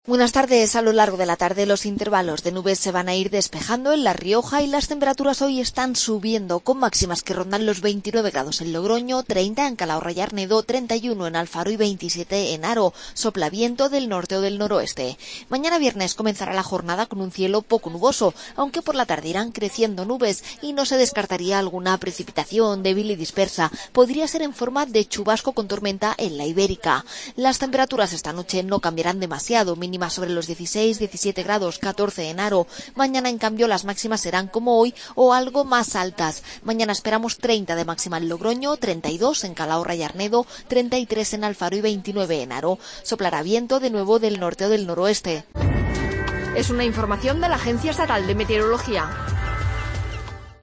Pronóstico del Tiempo, 24 de julio 2015